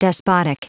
Prononciation audio / Fichier audio de DESPOTIC en anglais
Prononciation du mot despotic en anglais (fichier audio)